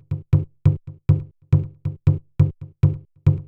BONG LINE -R.wav